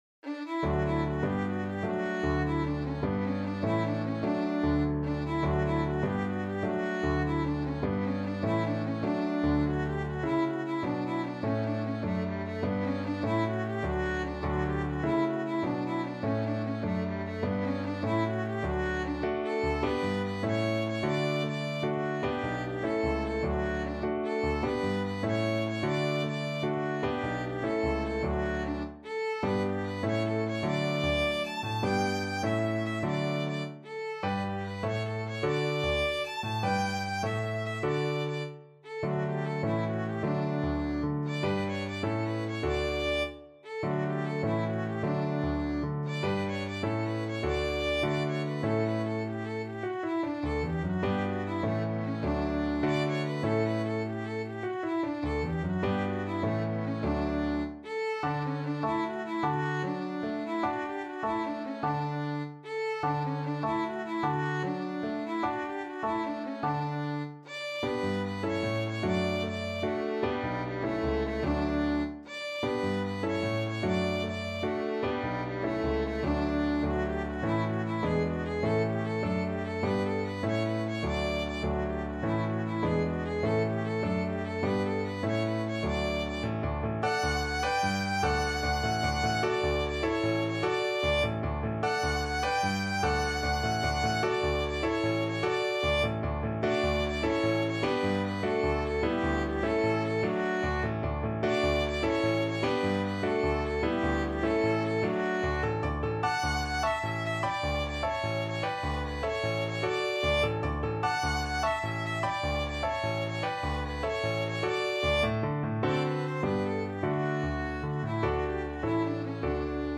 Violin
D major (Sounding Pitch) (View more D major Music for Violin )
6/8 (View more 6/8 Music)
Classical (View more Classical Violin Music)
sanz_canarios_VLN.mp3